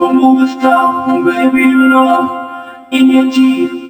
VOXVOCODE1-R.wav